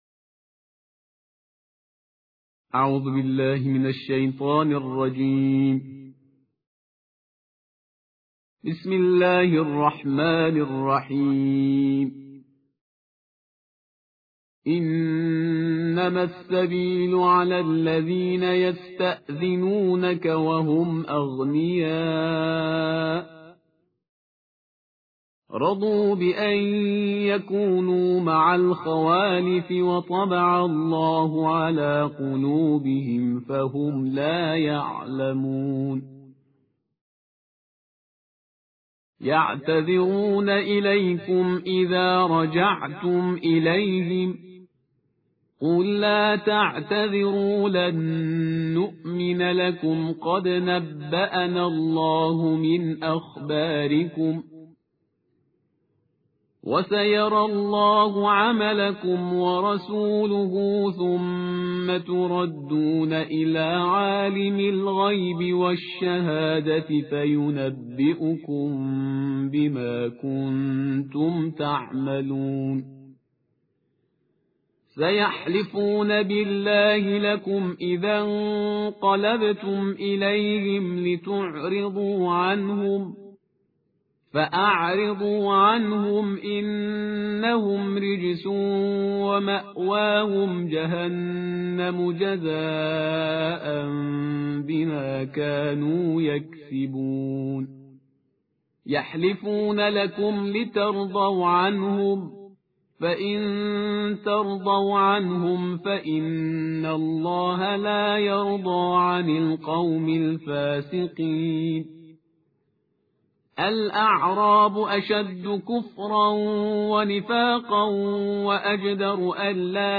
صوت/ ترتیل جزء دوازدهم قرآن با صدای پرهیزگار